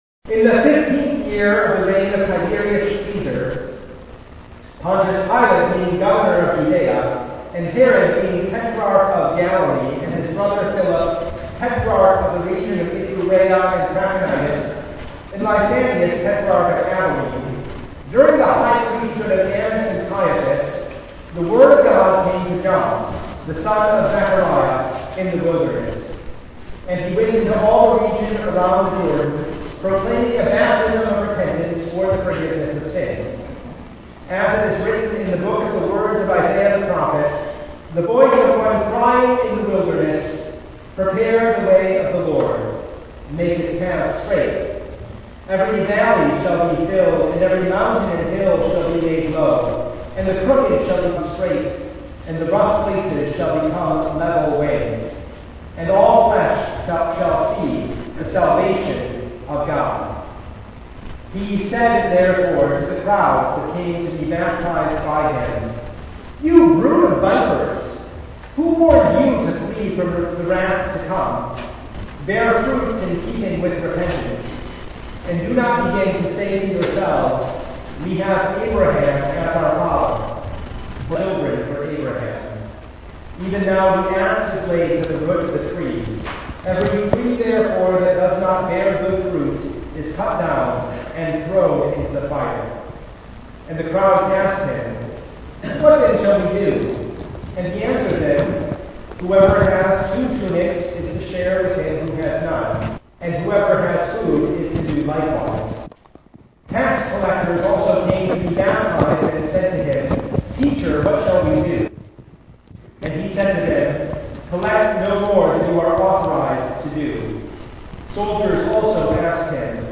Passage: Luke 3:1-22 Service Type: Bible Study